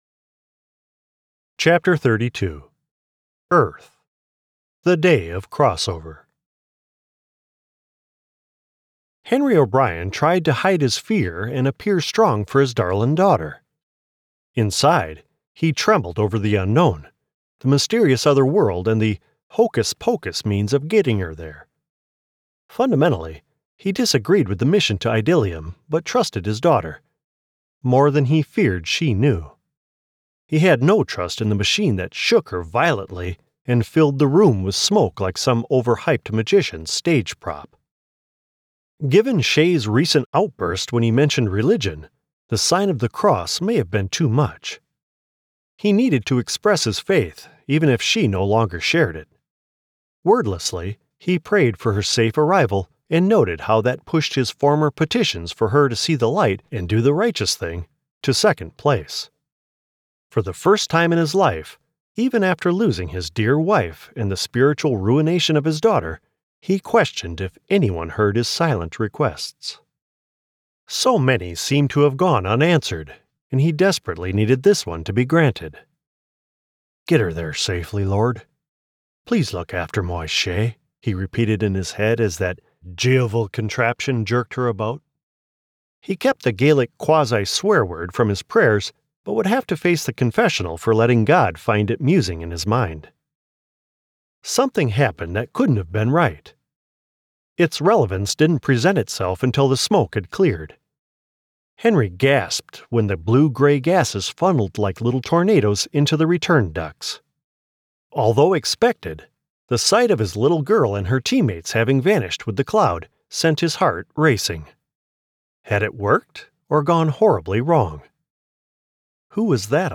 Older Sound (50+)
My voice is a rich baritone, a bit gravelly now that I'm in my mid-50s but still nice and strong, dignified but with lots of energy and expression, that is very well-suited to narrative deliveries for projects such as audiobooks, documentaries, explainers, and suitable broadcast-style advertisements.
Audiobooks
Words that describe my voice are baritone, gravelly, expressive.